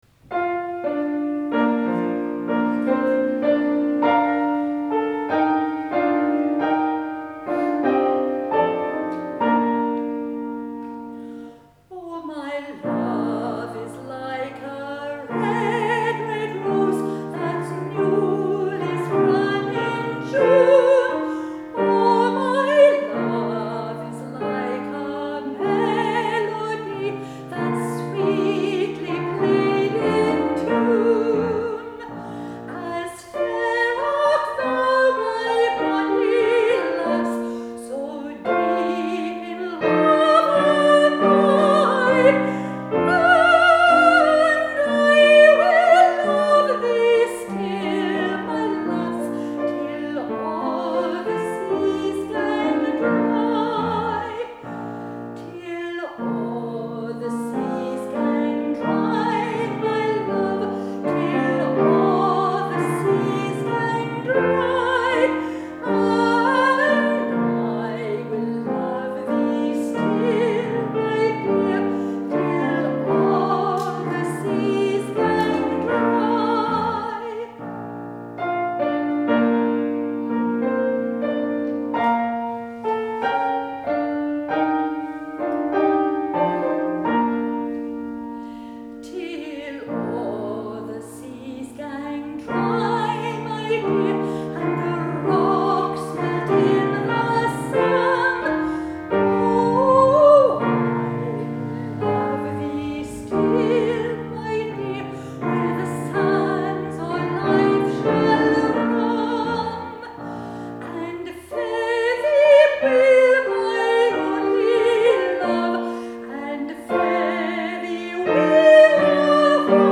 Octet Plus at Summerlea sings for "Ricochet"